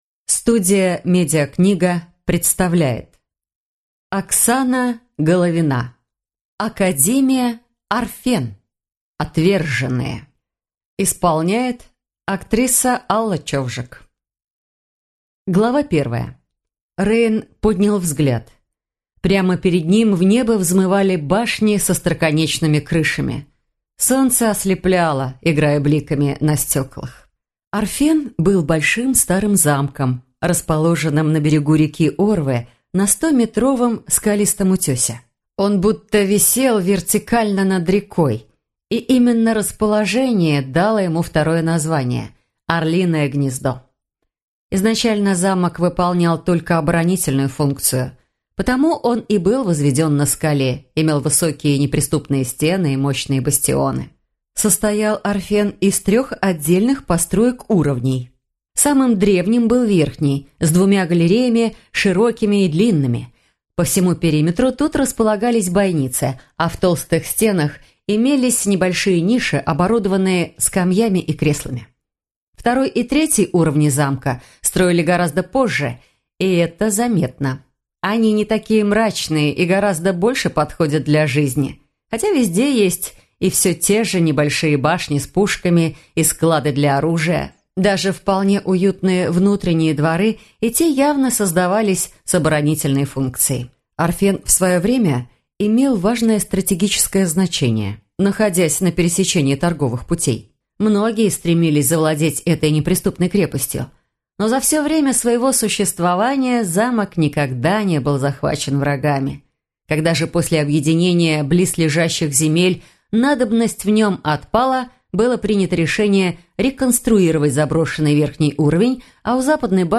Аудиокнига Академия Арфен. Отверженные | Библиотека аудиокниг